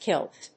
kilt /kílt/
• / kílt(米国英語)